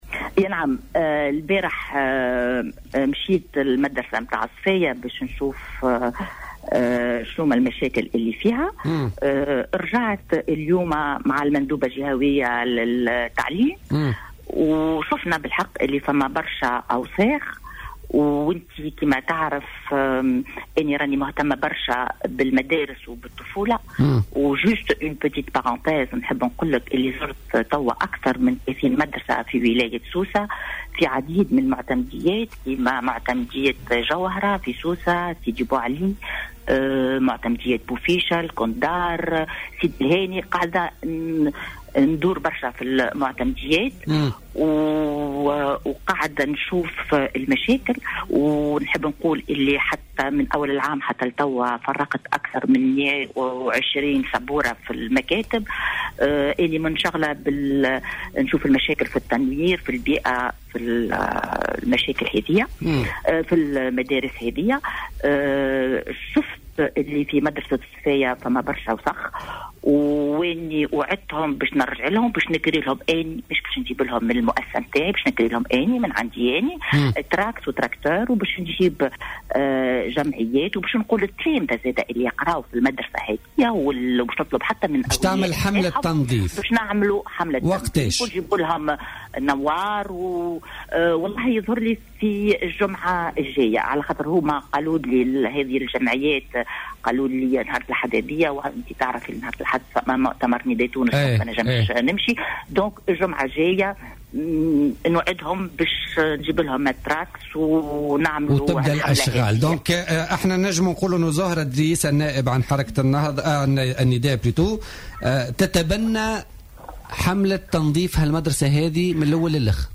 وقالت إدريس في تصريح ل"الجوهرة اف أم" إنها قد عاينت تراكم الفضلات بشكل كبير في المدرسة، مشيرة إلى أنها ستقوم بكراء معدات لنقلها وذلك في إطار حملة نظافة بالتعاون مع الأولياء والتلاميذ و عدد من الجمعيات بداية من الأسبوع القادم. وأضافت أنها ستقوم أيضا بحملات نظافة في مدارس أخرى في الجهة، مشيرة إلى أنها تتابع منذ مدّة وضعيات المدارس في عدد من معتمديات سوسة وتقدّم مساعدات لها.